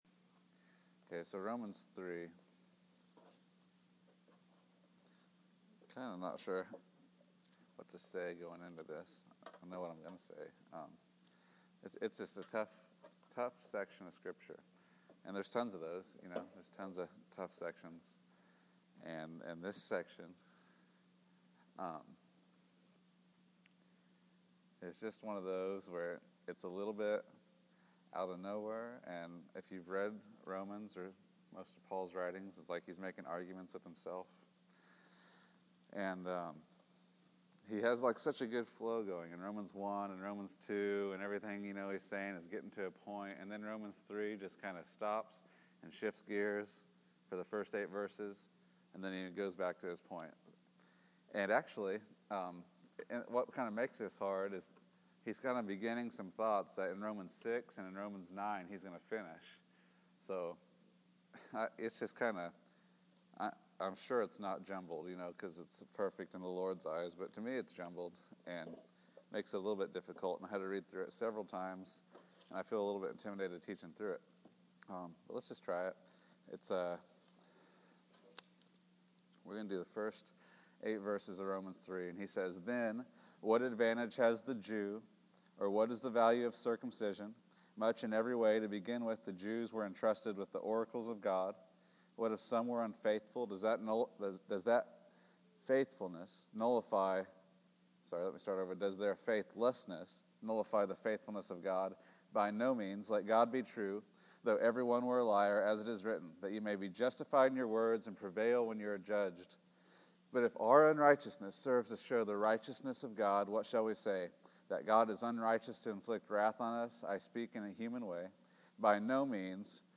Romans 3:1-8 January 11, 2015 Category: Sunday School | Location: El Dorado Back to the Resource Library Let God be true and every man a liar.